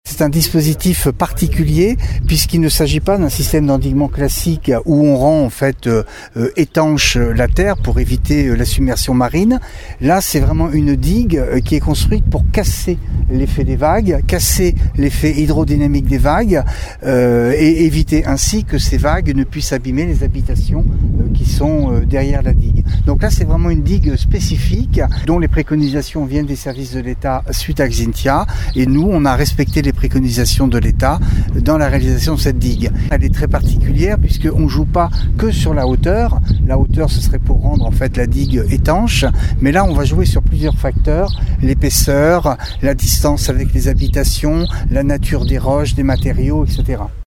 Patrice Raffarin est conseiller départemental en charge du Plan Digues pour la Charente-Maritime :